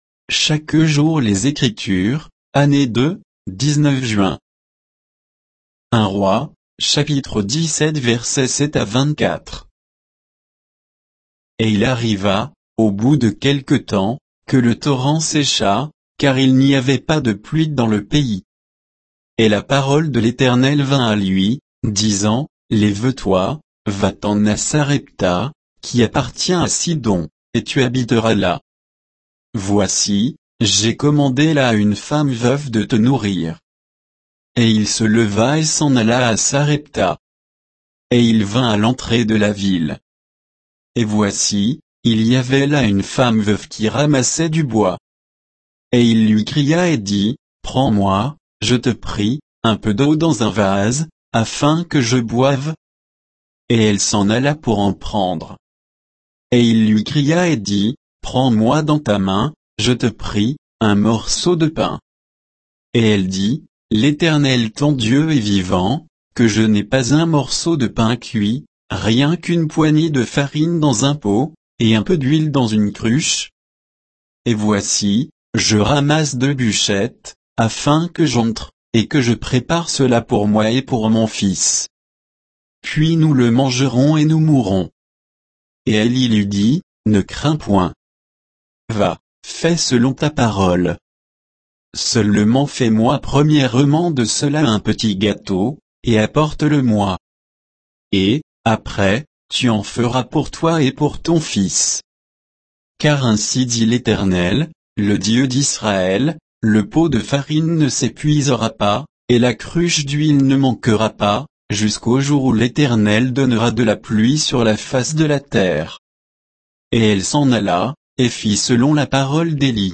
Méditation quoditienne de Chaque jour les Écritures sur 1 Rois 17